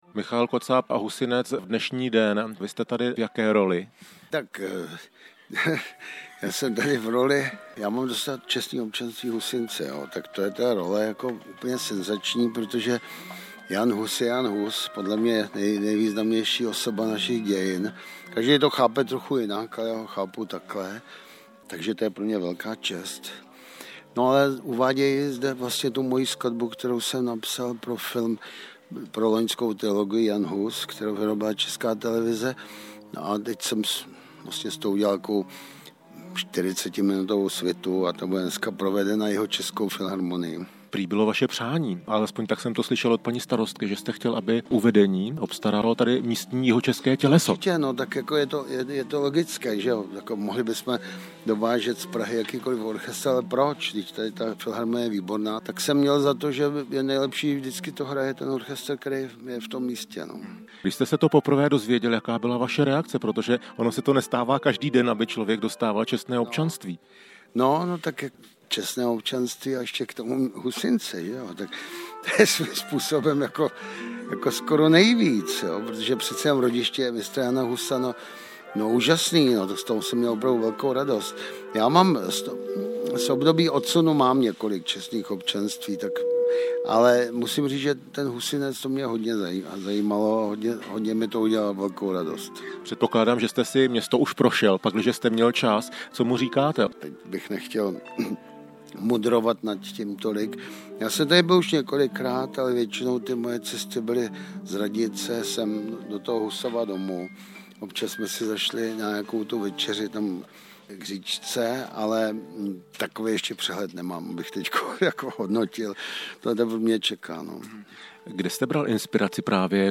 Rozhovor s Michaelem Kocábem
Husinec, 6. 7. 2016
rozhovormichaelkocab.mp3